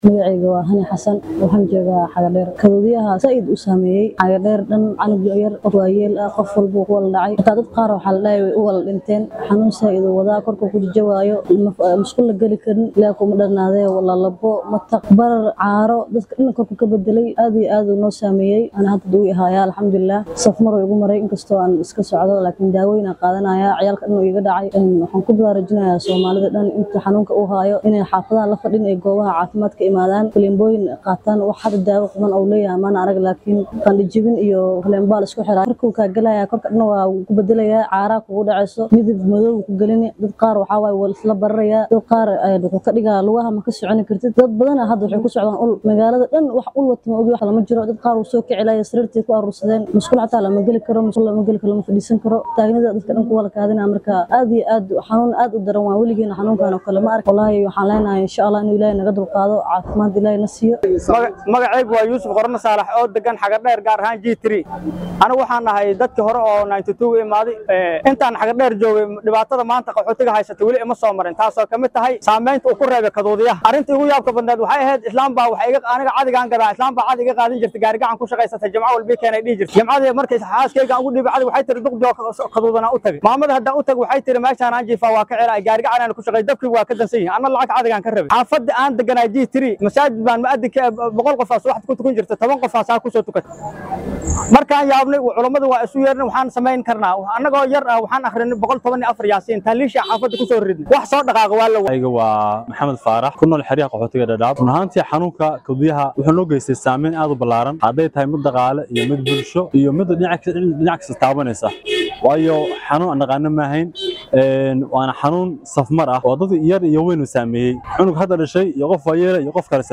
Haddaba, qaar ka mid ah shacabka xerada qaxootiga ee Xagardheer ayaa ka warramay saameynta uu cudurkan ku yeshay bulshada deegaanka.
Codka-bulshada-Xagardheer.mp3